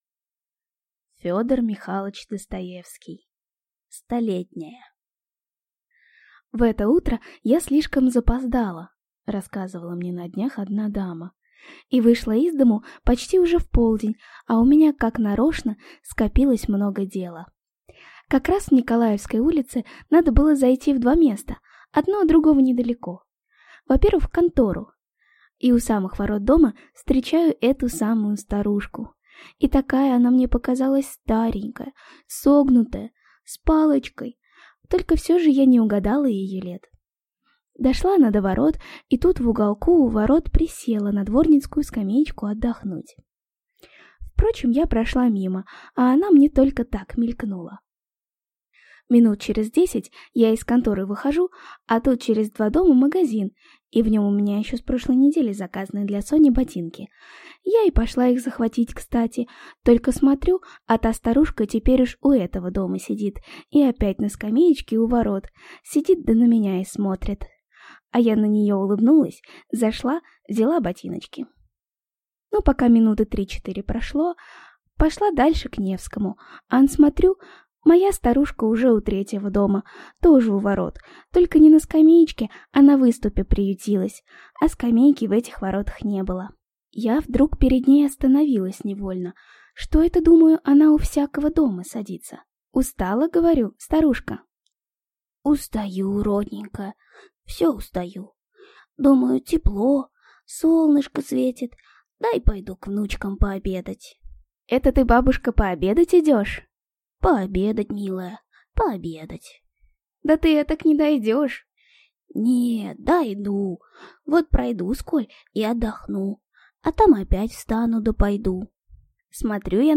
Аудиокнига Столетняя